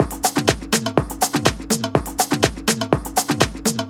• iberic house top loop fill.wav
iberic_house_top_loop_fill_Ott.wav